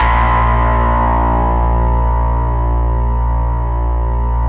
RESMET A#1-R.wav